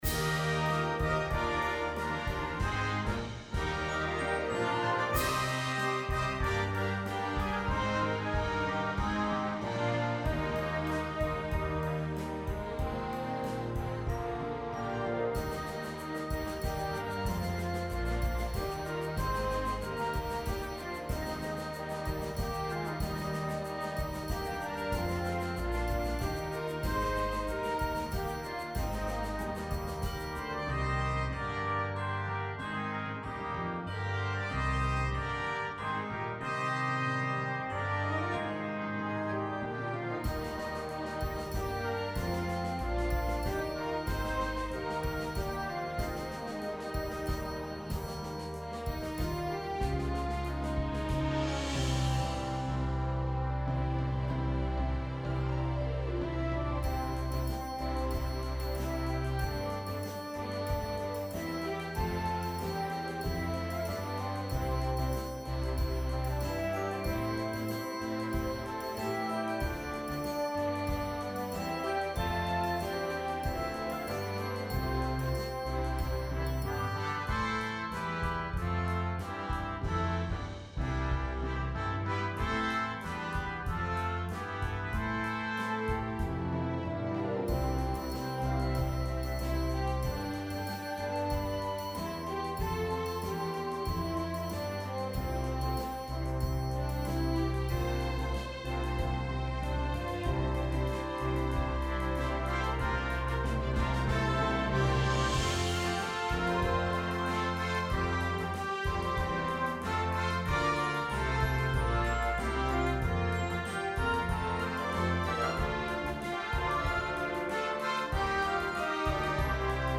A modern setting of this ageless hymn.